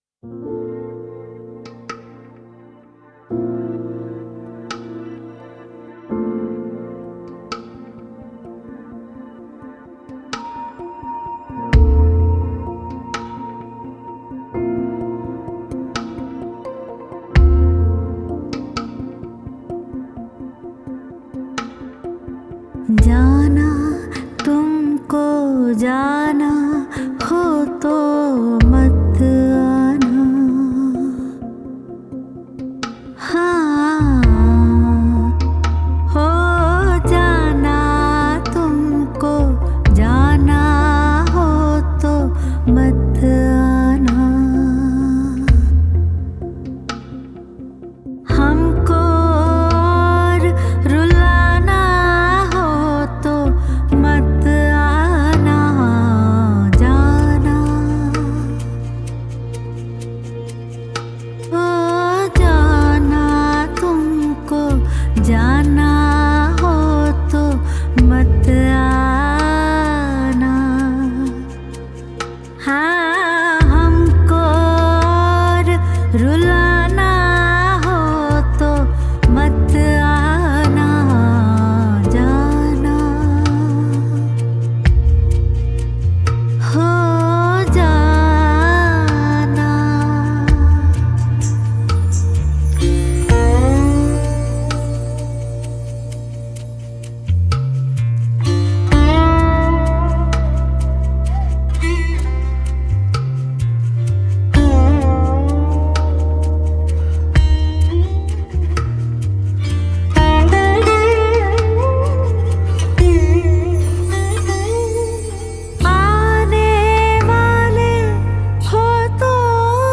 Sitar